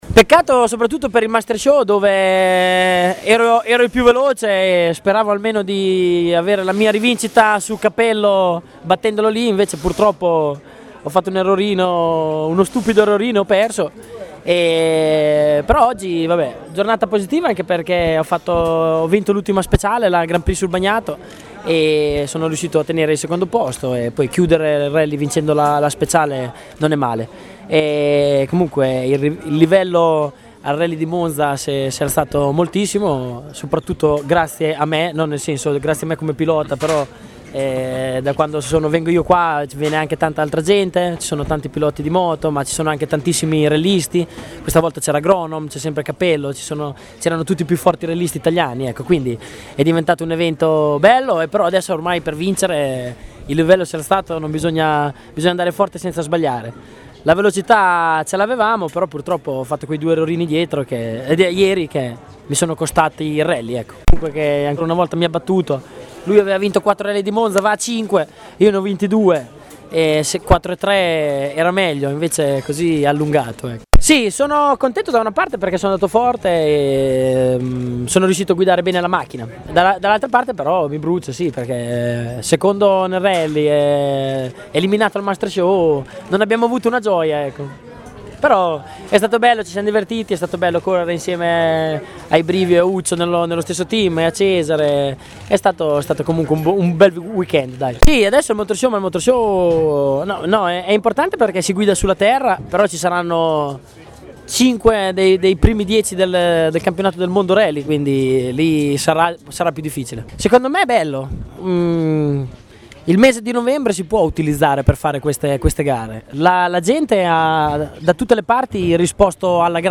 Rossi ci racconta la sua gara: ascolta l'audio